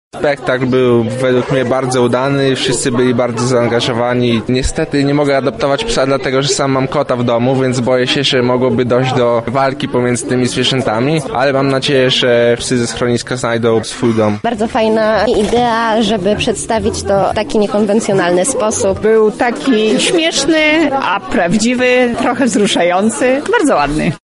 Publiczność podzieliła się swoimi wrażeniami :